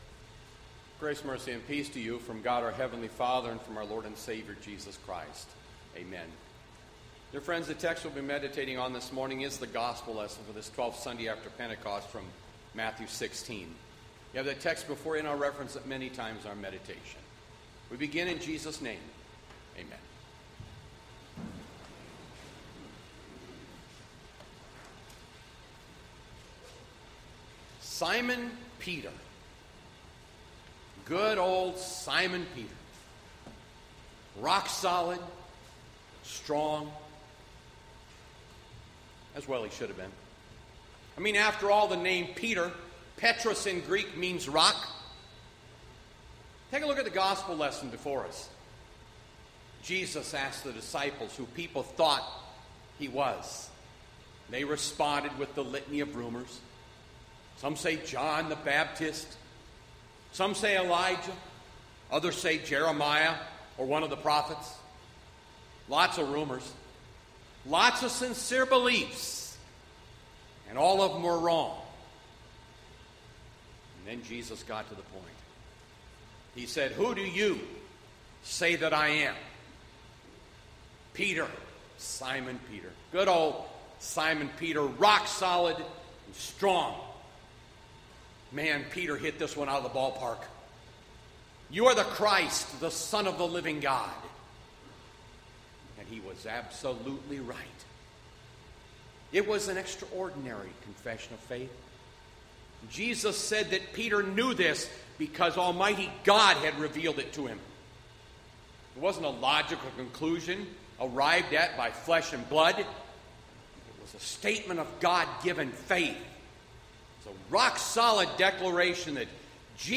Bethlehem Lutheran Church, Mason City, Iowa - Sermon Archive Aug 23, 2020